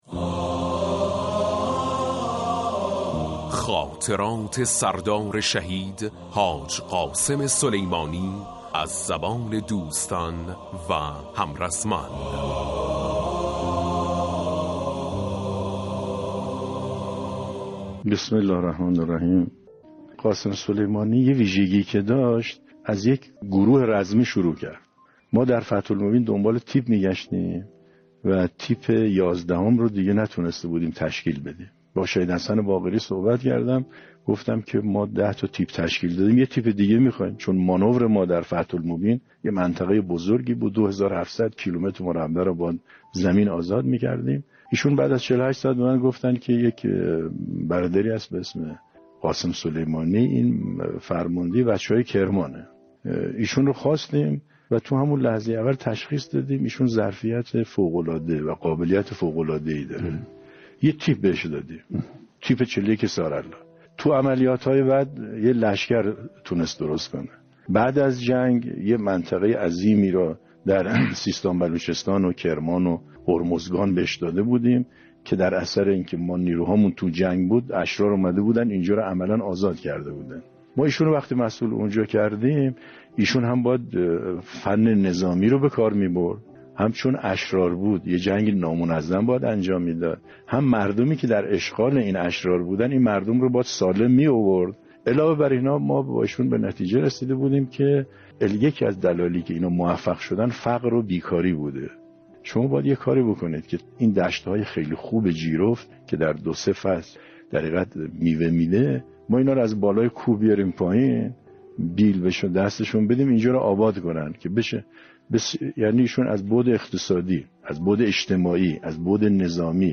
بیان خاطراتی از خصوصیات شهید حاج قاسم سلیمانی و حماسه آفرینی های او در عملیات بیت المقدس و کربلای ۵ به روایت سرلشکر محسن رضایی